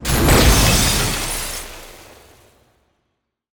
sfx_skill 09_2.wav